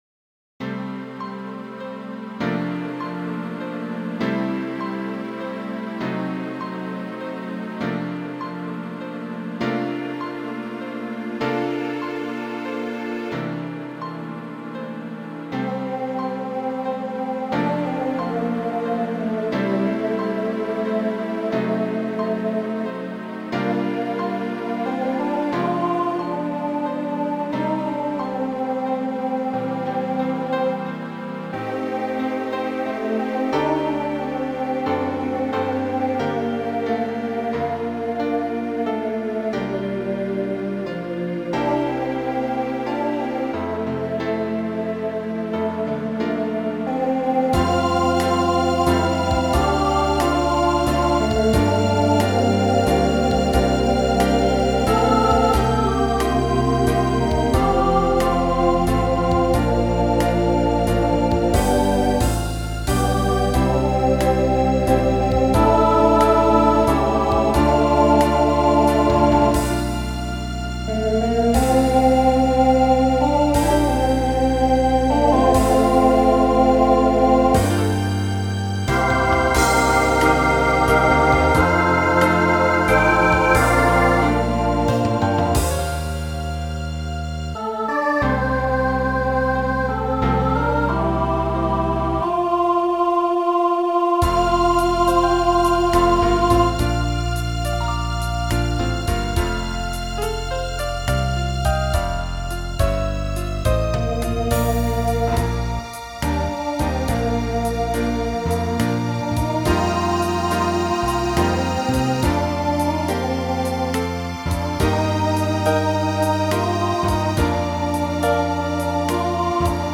1990s Show Function Ballad